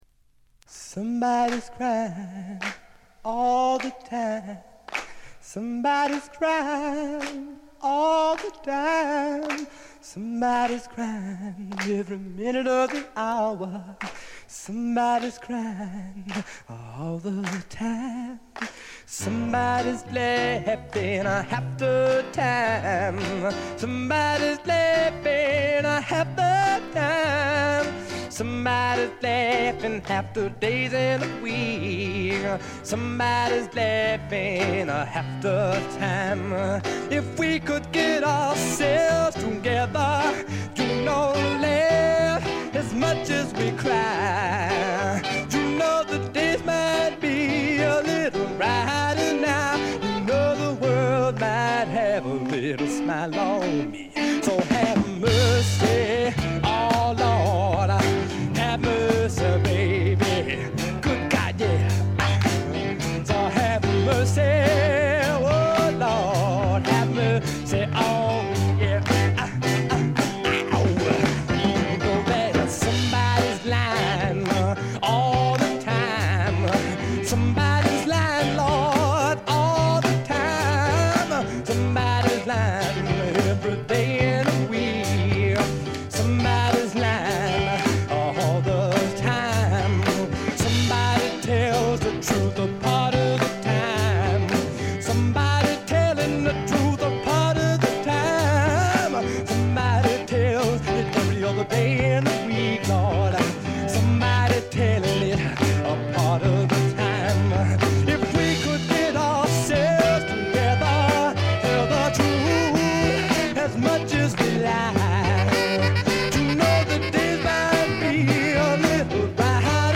ロックとソウル境界線を行き来する傑作です。
試聴曲は現品からの取り込み音源です。